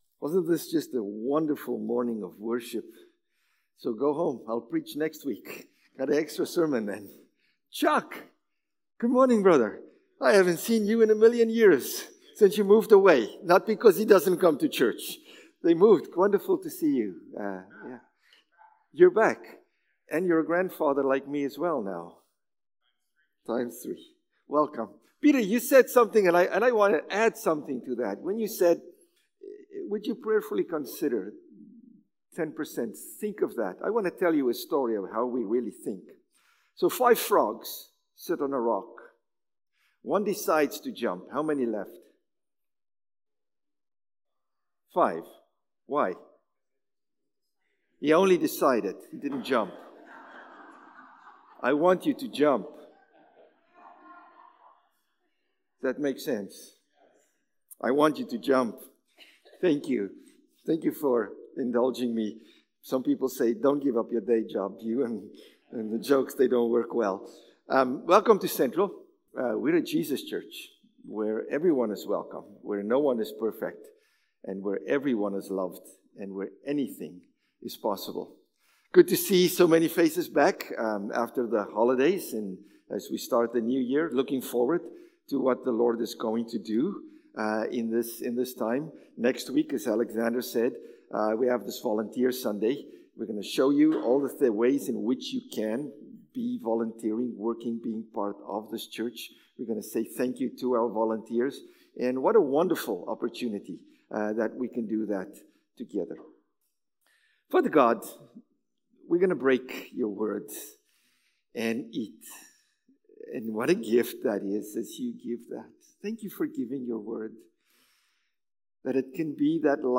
September-8-Sermon.mp3